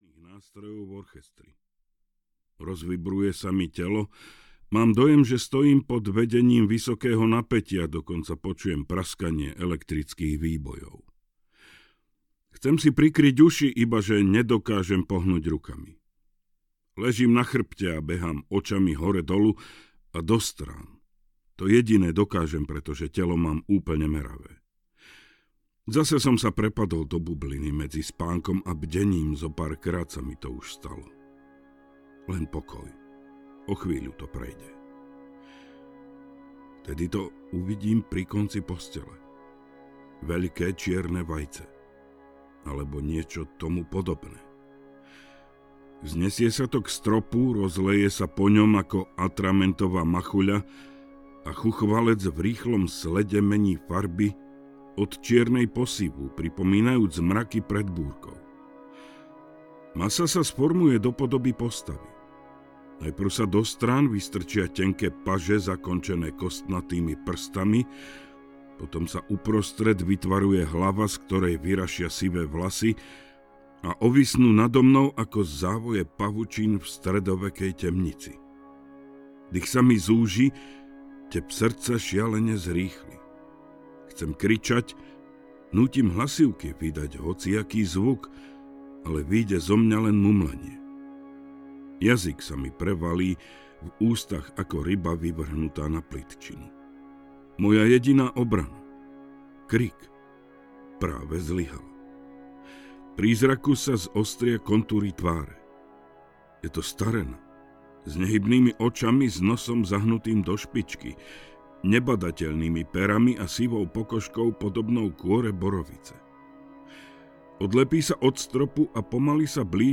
Deväť: Príbeh Ďatlovovej výpravy audiokniha
Ukázka z knihy